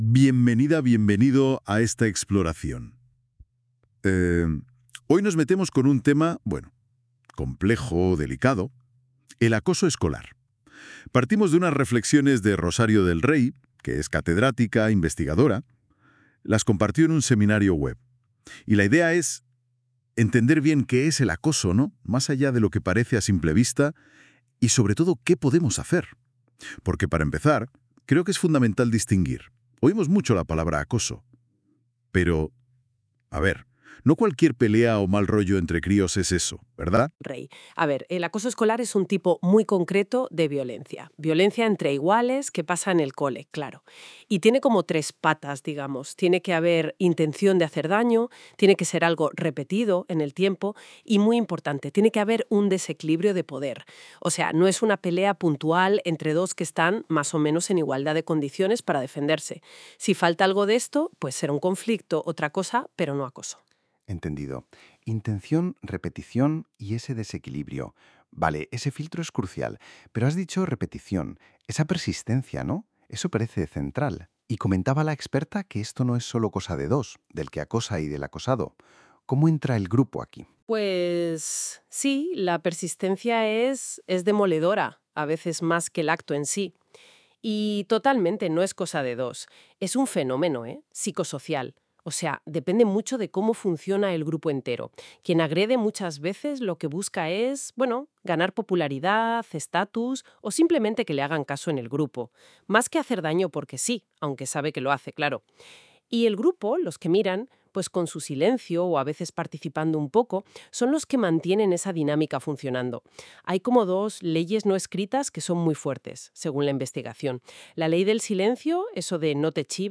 Resumen de audio de la ponencia: